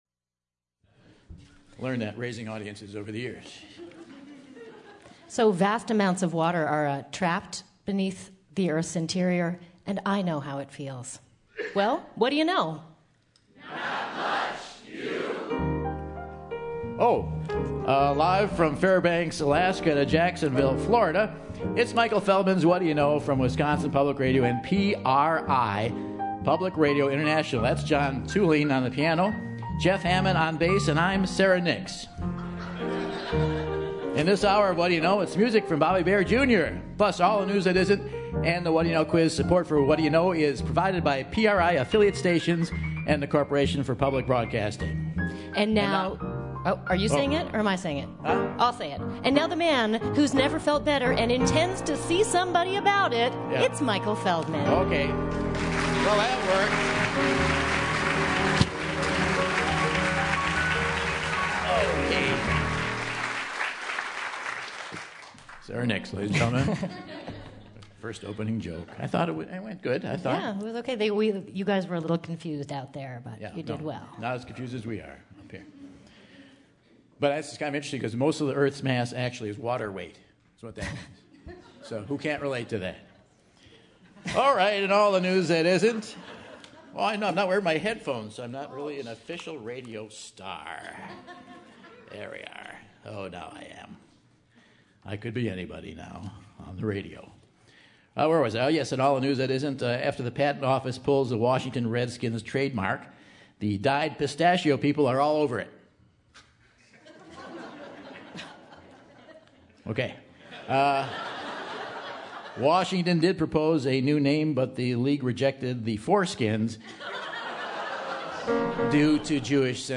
June 21, 2014 - Madison, WI - Monona Terrace | Whad'ya Know?